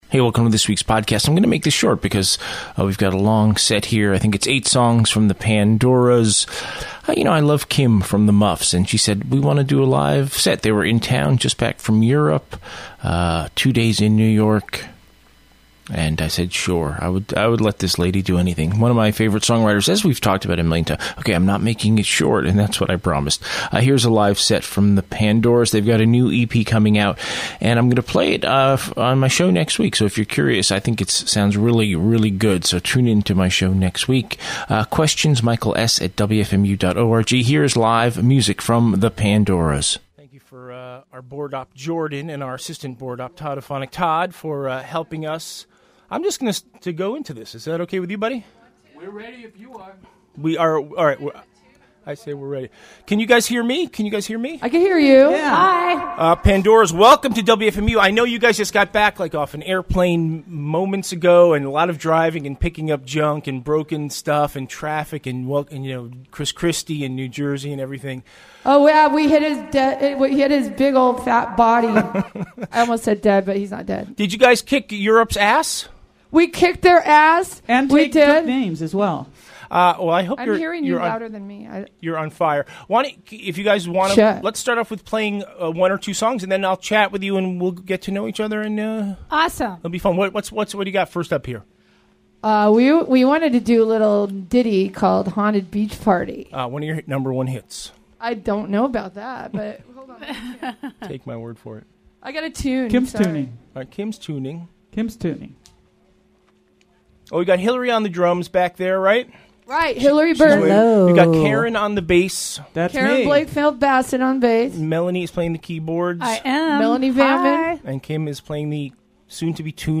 Guests: The Pandoras - Live in the studio! from Sep 12, 2015